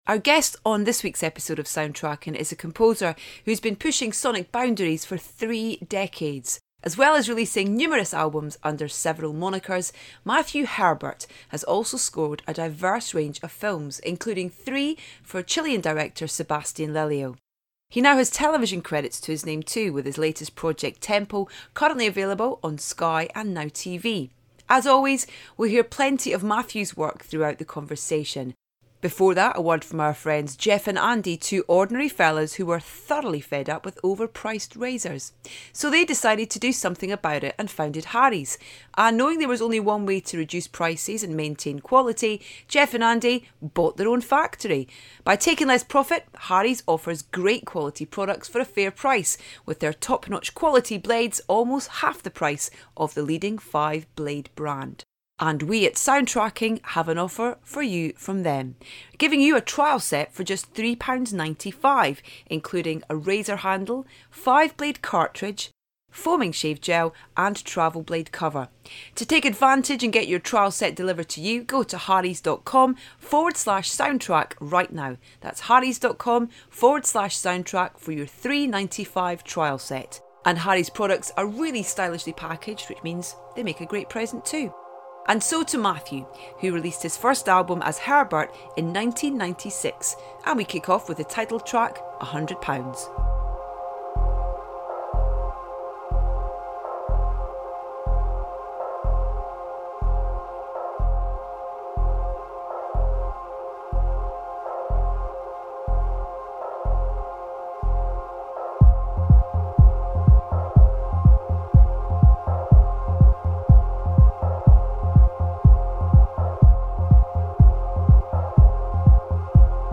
Edith's guest on the latest episode of Soundtracking is a composer who has been pushing sonic boundaries for three decades.
He now has television credits to his name too, with his latest project Temple currently available on Sky and Now TV. As always, you'll hear plenty of Matthew's work throughout the conversation.